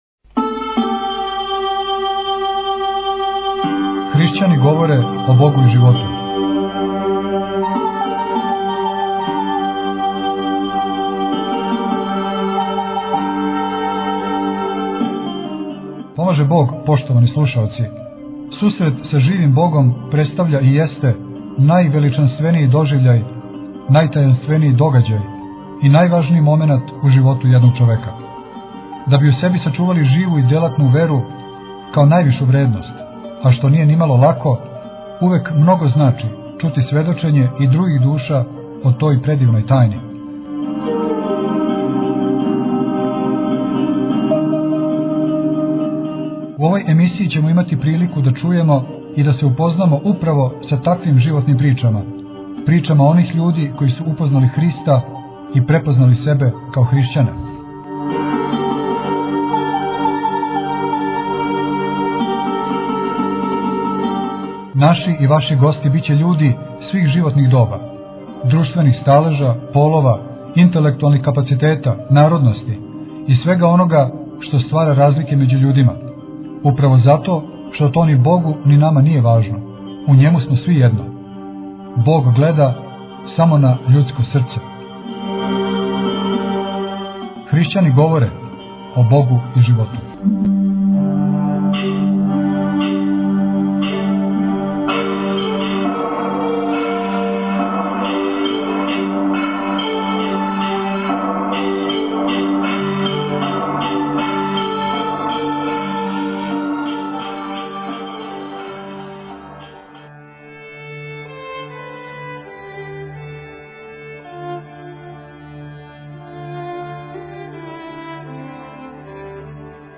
снимио је ову заиста потресну емисију у манастиру Острог